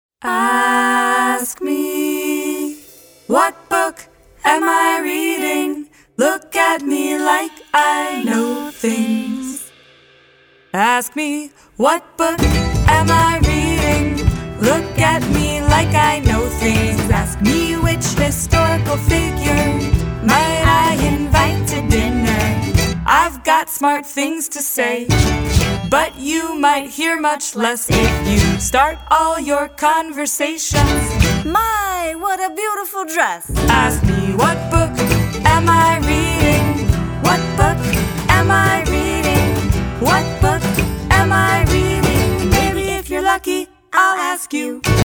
All tracks except Radio Edits include scripted dialogue.